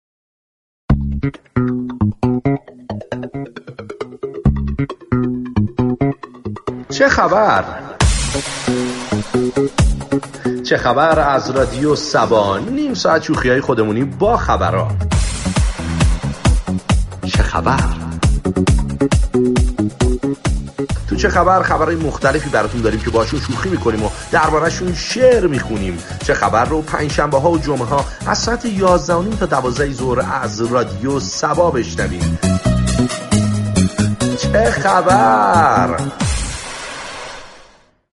رادیو صبا روز جمعه در برنامه "چه خبر" بانگاهی طنز به مرور آداب احترام در كشورهای مختلف می پردازد.